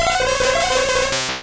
Cri d'Hypnomade dans Pokémon Rouge et Bleu.